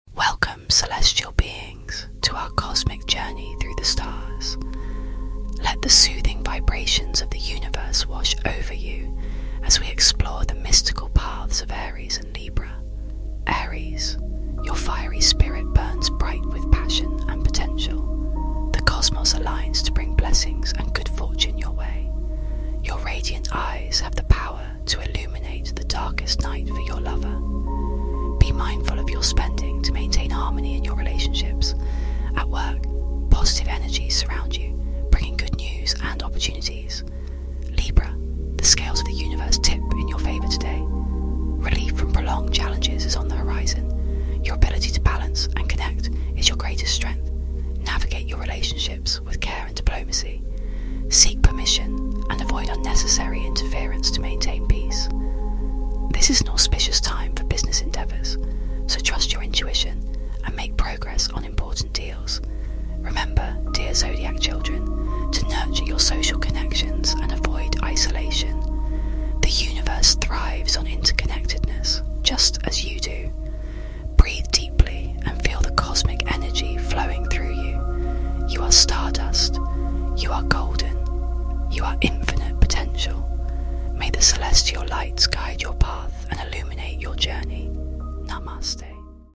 Mystical ASMR horoscope journey for sound effects free download